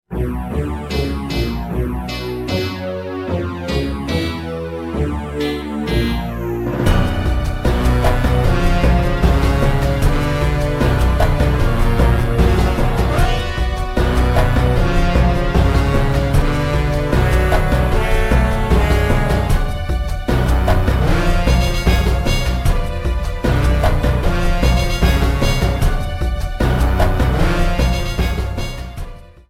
Ripped from the remake's files
trimmed to 29.5 seconds and faded out the last two seconds